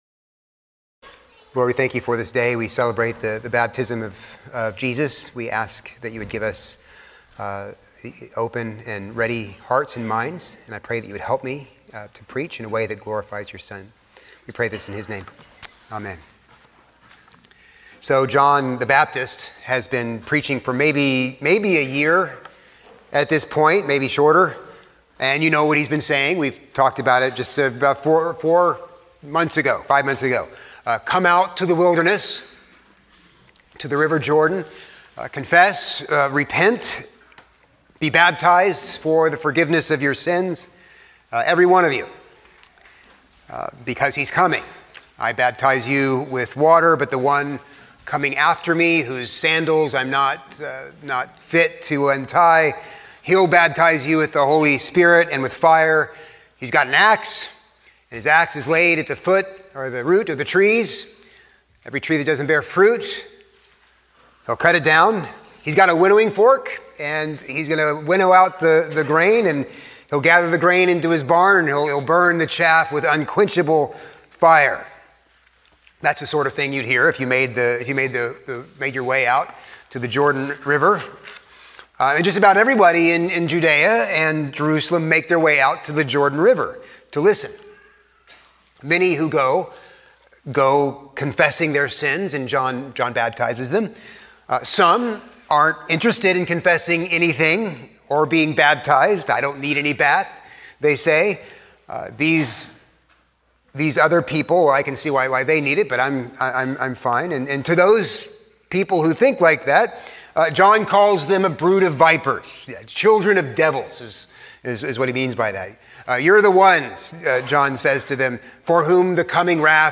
A sermon on Matthew 3:13-17